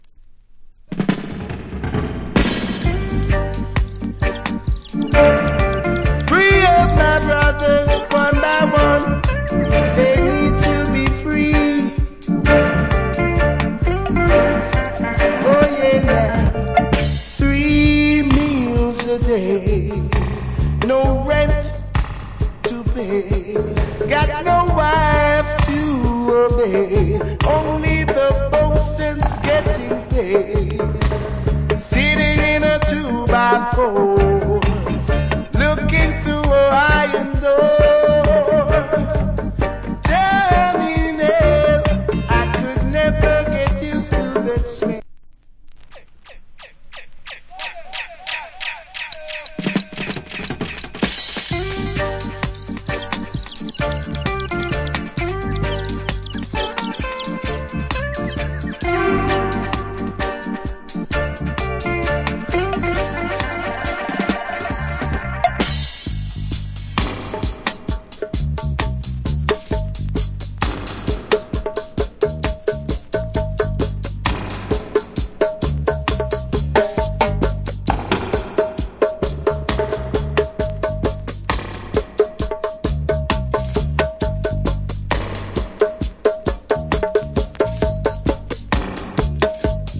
TOP >ROOTS ROCK
Cool Roots Rock Vocal.